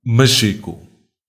Machico (Portuguese pronunciation: [mɐˈʃiku]
Pt-pt_Machico_FF.ogg.mp3